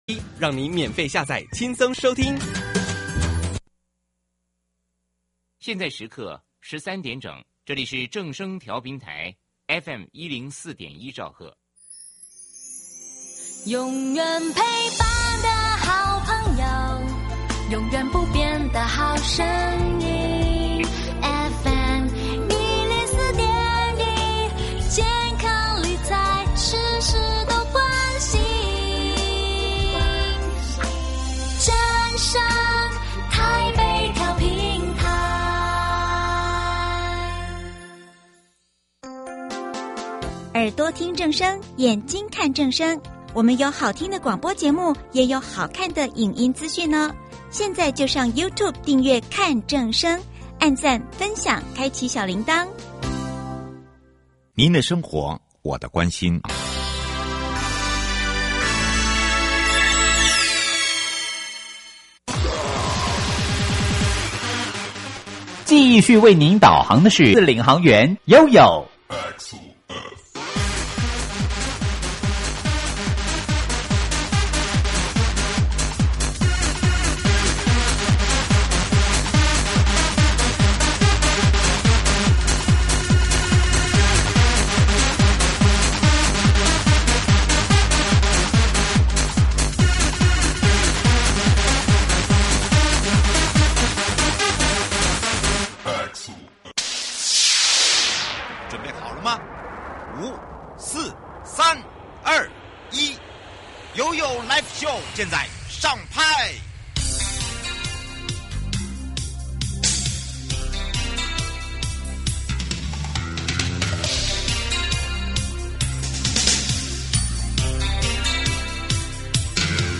節目內容： 國土署 都市基礎工程組 高雄市政府交通局 劉建邦副局長(二)